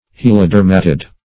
helodermatidae - definition of helodermatidae - synonyms, pronunciation, spelling from Free Dictionary